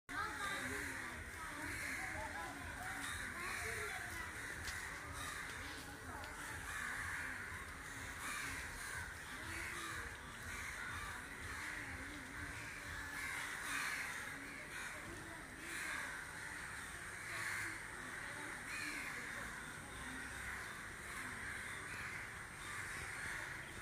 עורבים – משפחה. מטיילת.
אם אתה לא רואה – תקשיב ותשמע.
Ravens-2.m4a